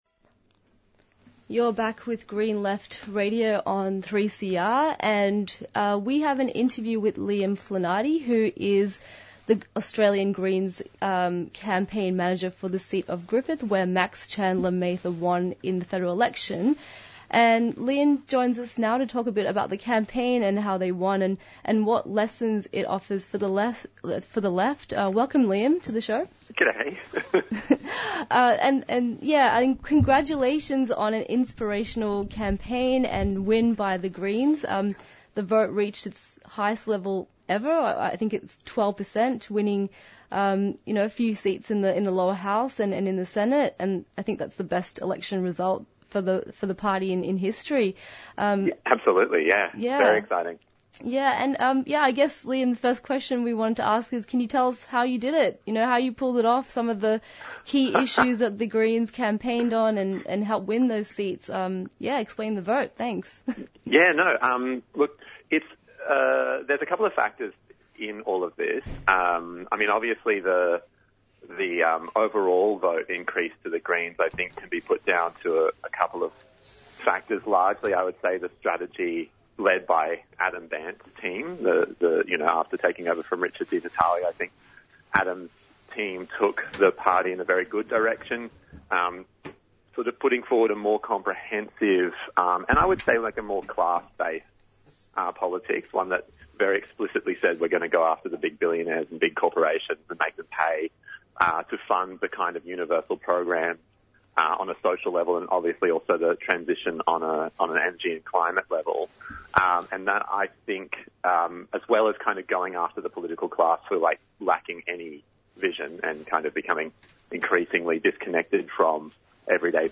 Newsreports Headline news discussion from the presenters responding to the recent mass shootings in the United States specifically the Robb Elementary School shooting which killed over 19 students and two teachers, the case for gun regulation in the United States and the roots of the violence.
Interviews and Discussions